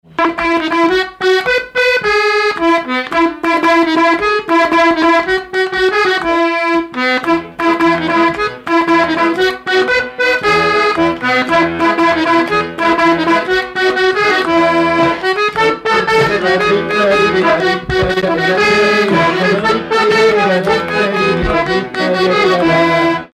Air
Pièce musicale inédite